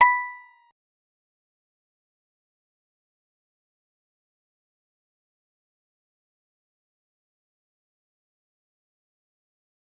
G_Musicbox-B5-pp.wav